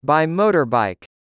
18. by motorbike /baɪ ˈməʊtərbaɪk/: bằng xe gắn máy